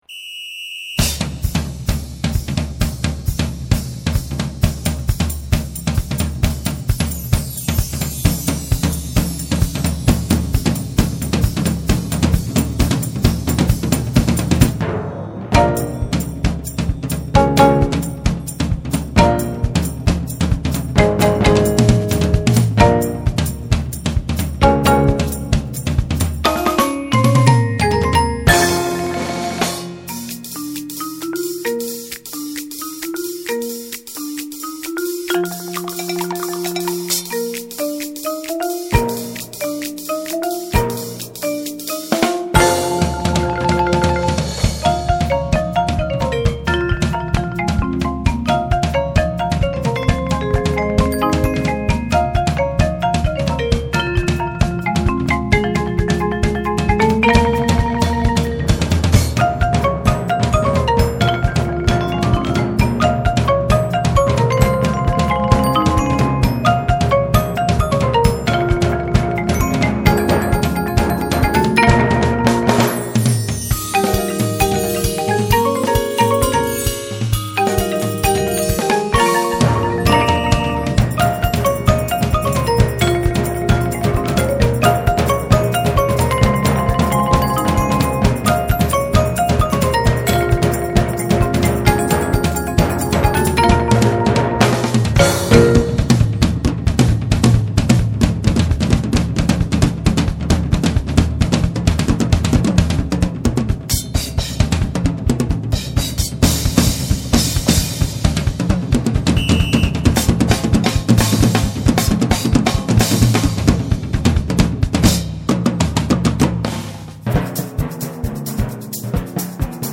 Voicing: Percussion Choir